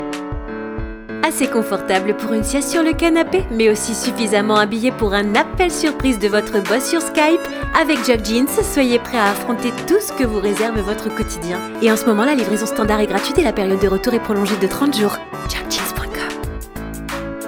Une voix, plusieurs styles
Une voix douce, posée, rassurante, souriante, dynamique, punchy
Je dispose donc d’un studio de post-production à l’acoustique étudiée et parfaitement insonorisé.